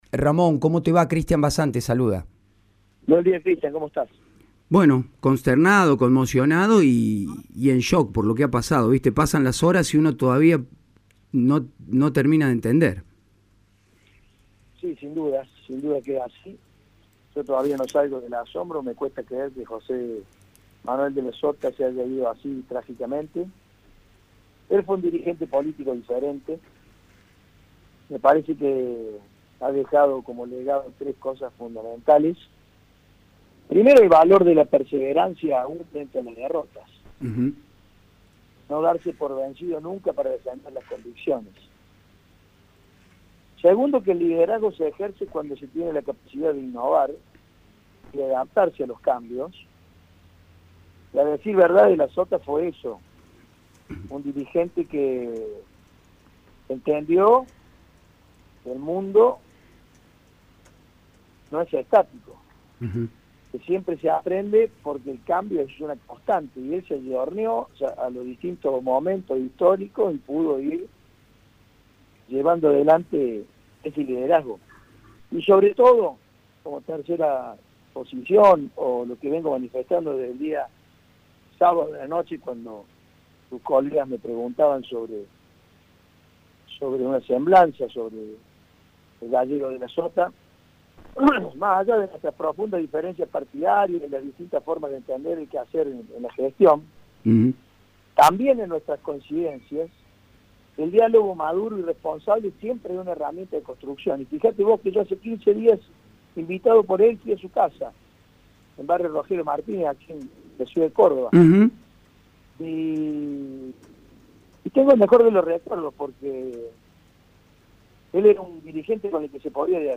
AUDIO: Ramón Mestre, intendente de Córdoba.